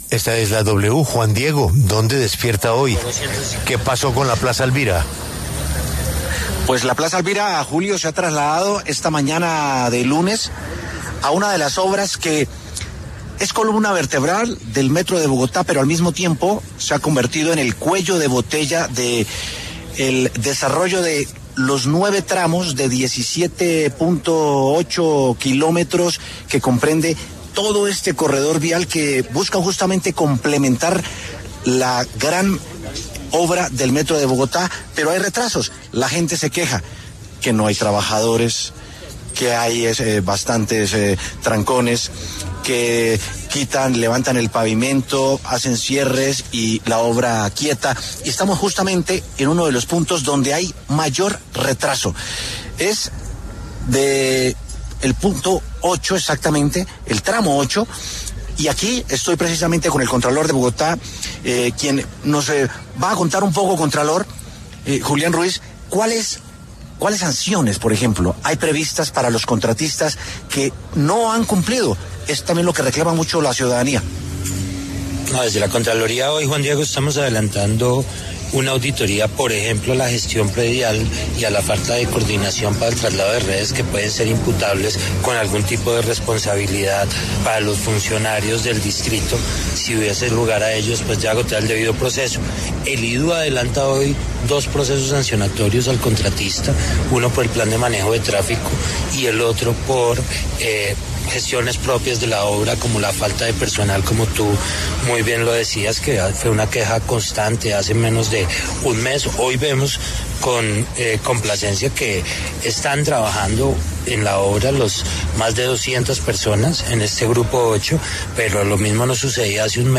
El contralor de Bogotá, Julián Ruiz, conversó con La W acerca de los retrasos en la construcción de los nueve tramos de más de 17 kilómetros del metro de la capital del país y las sanciones a los contratistas que no cumplen con los tiempos acordados.